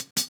Closed Hats
duz_hat.wav